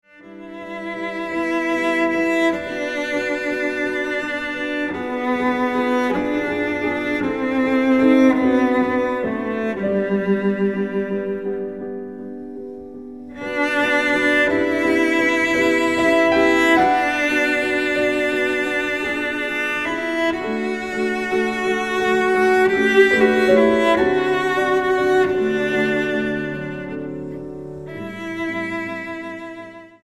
violoncello
piano